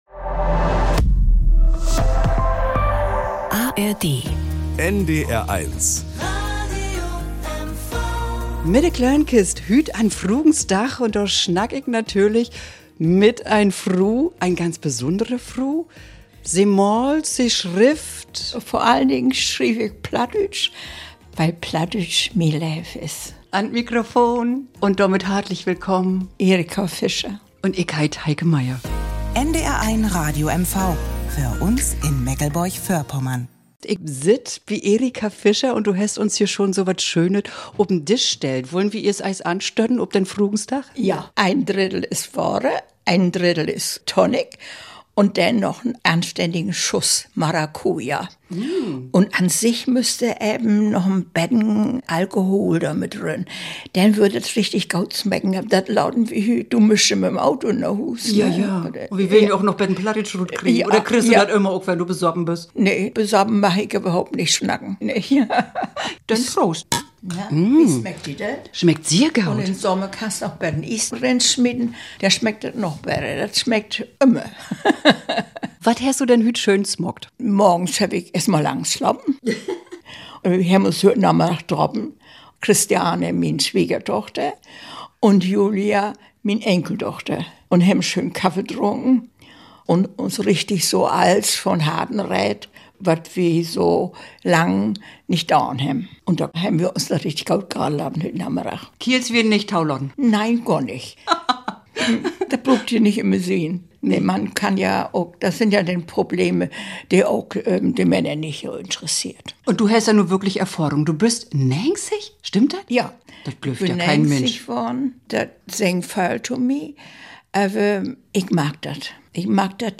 Das Plattdeutsch der Griesen Gegend spricht sie selbstverständlich, so wie sie es von Zuhause mitbekommen hat. Und in ihrer Sprache erzählt sie Geschichten voller Wärme, überraschend in Wortwahl und Rhythmus, bildhaft in den Beschreibungen.